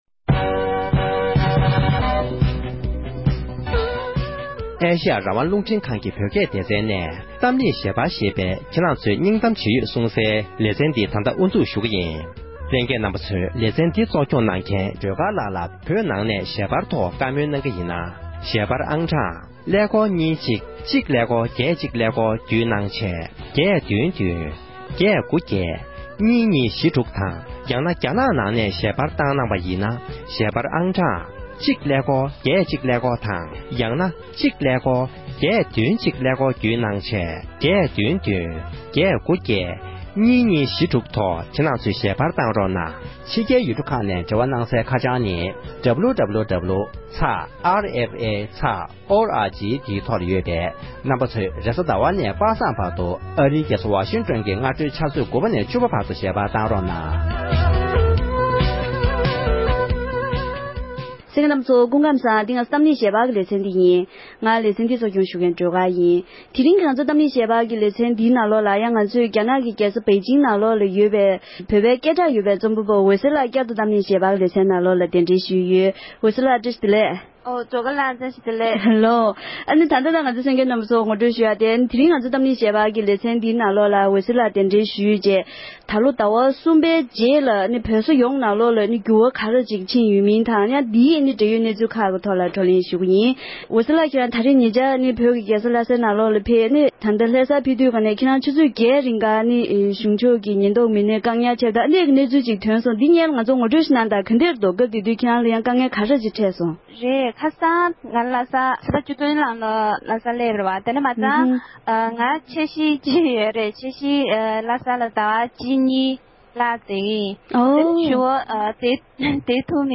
བོད་ནང་གི་འབྲེལ་ཡོད་མི་སྣར་བོད་ཀྱི་ས་ཁུལ་ཁག་ནང་འགྱུར་བ་ཇི་འདྲ་ཕྱིན་ཡོད་མེད་སྐོར་གླེང་མོལ་ཞུས་པ།